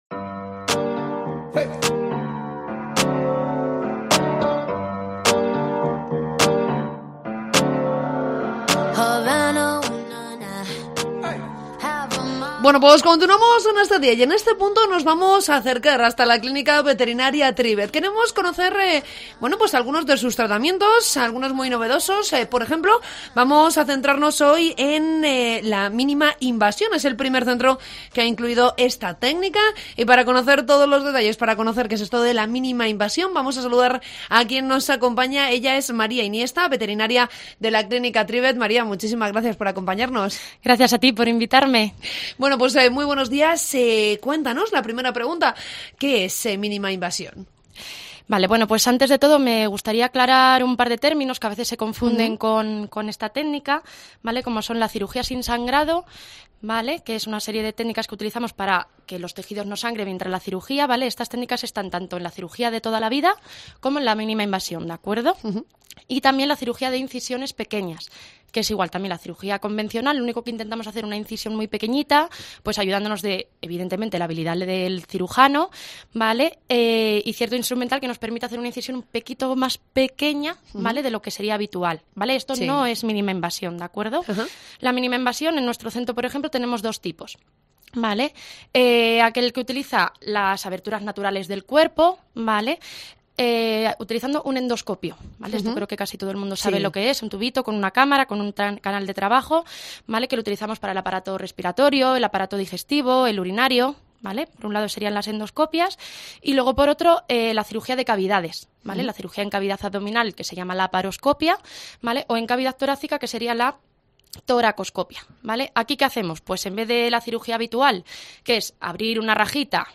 Entrevista con la veterinaria